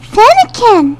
FENNEKIN.mp3